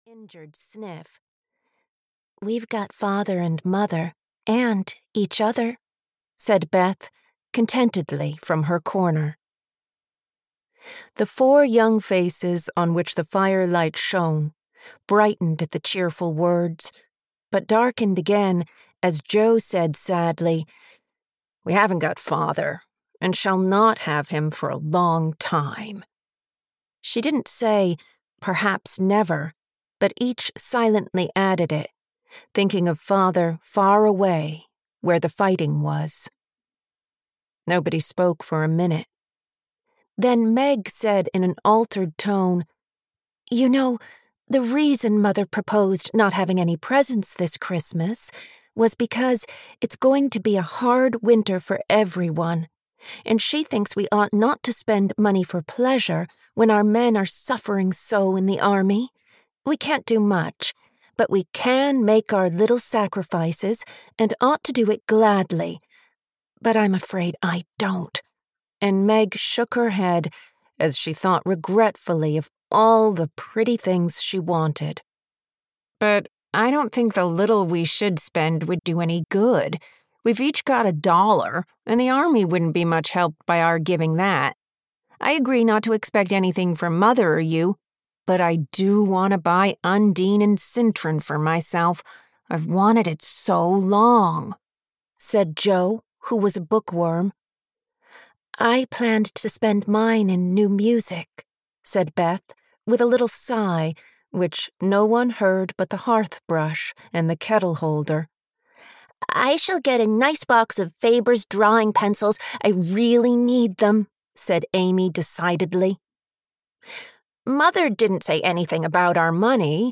Little Women (EN) audiokniha
Ukázka z knihy